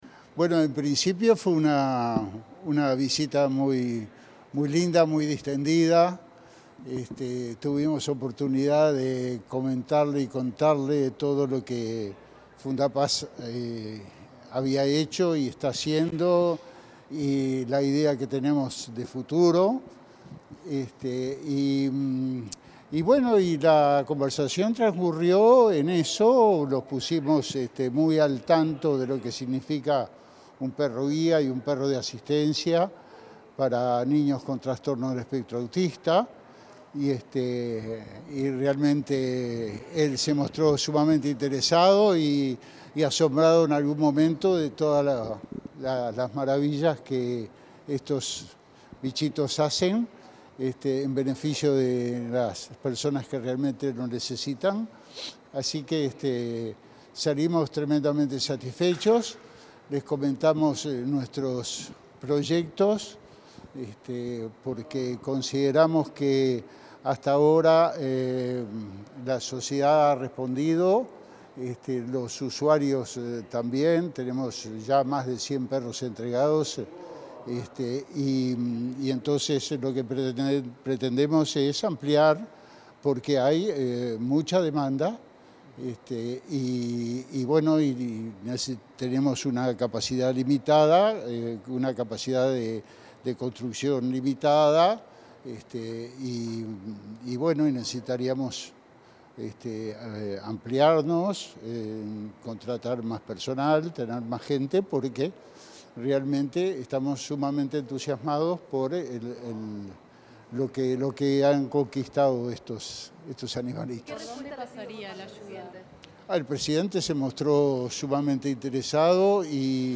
dialogó con la prensa en la Torre Ejecutiva,